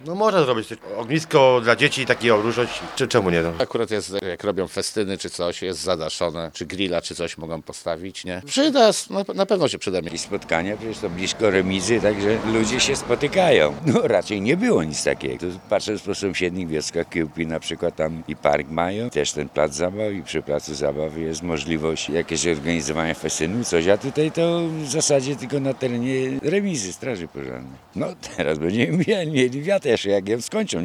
– To będzie miejsce spotkań – mówią mieszkańcy zielonogórskich Jarogniewic: